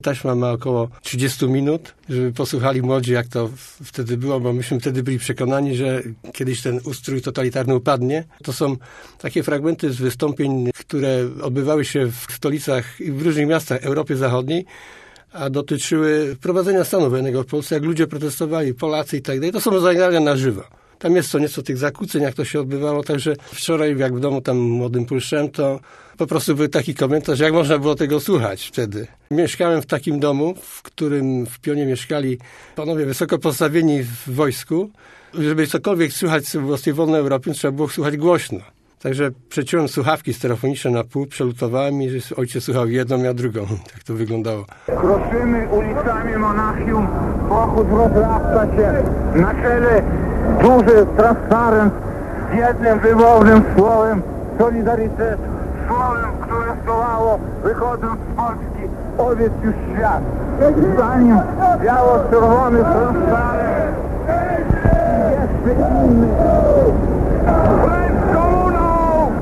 To nagrania audycji Radia Wolna Europa i Głosu Ameryki.